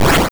Explosion3.wav